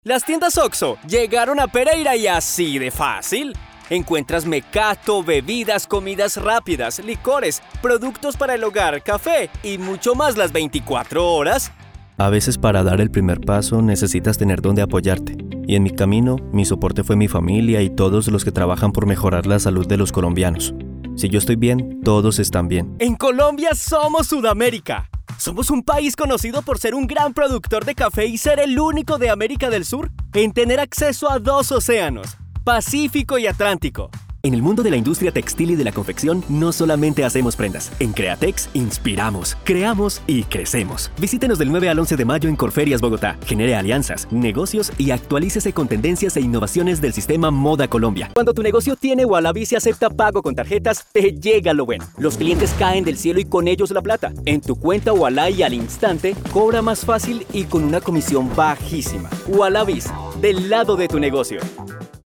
Spanisch (Kolumbien)
Konversation
Überzeugend
Freundlich